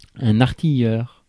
Prononciation
Prononciation France: IPA: /aʁ.ti.jœʁ/ Le mot recherché trouvé avec ces langues de source: français Traduction 1.